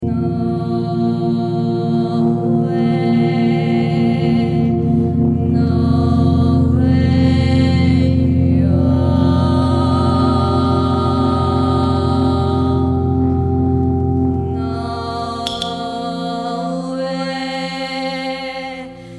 Voice, Recorder and Percussions
Theremin and Electric instruments), Live! available media